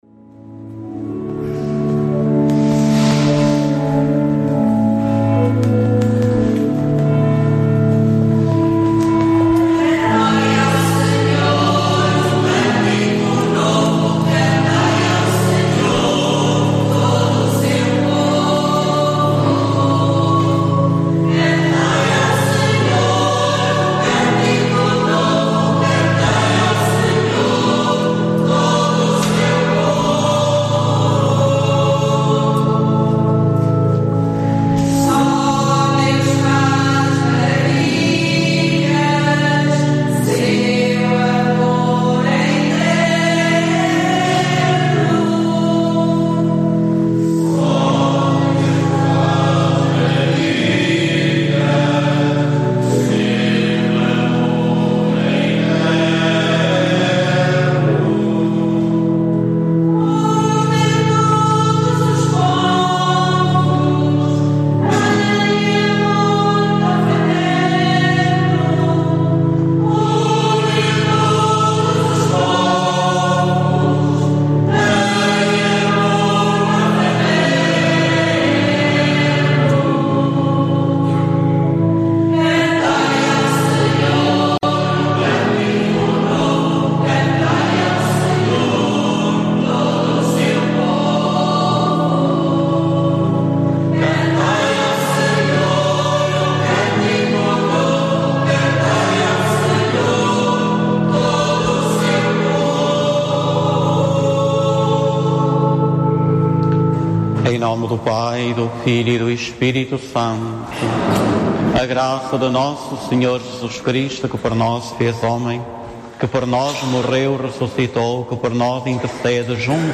A Rádio Clube de Lamego transmite todos os Domingos a Eucaristia, em direto, desde o Santuário de Nossa Senhora dos Remédios em Lamego a partir das 10 horas.
com a participação do Coro do Santuário de Nossa Senhora dos Remédios.